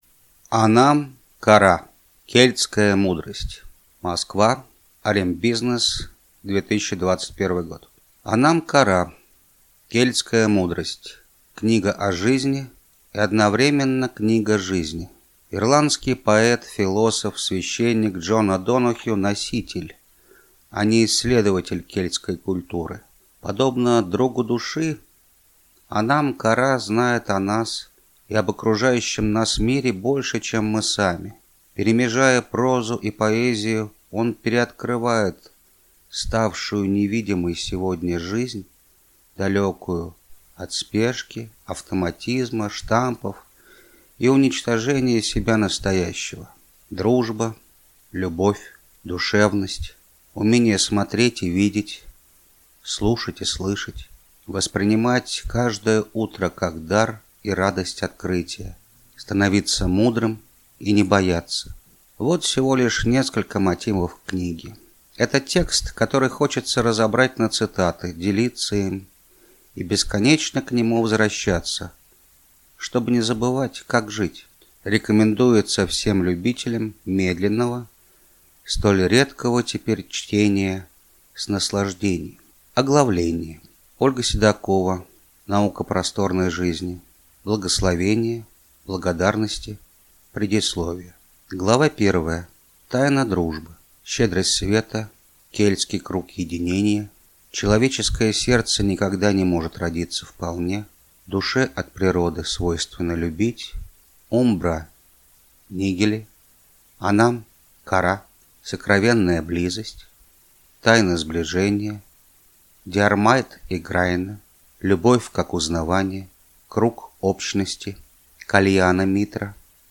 Аудиокнига Anam ċara. Кельтская мудрость | Библиотека аудиокниг
Прослушать и бесплатно скачать фрагмент аудиокниги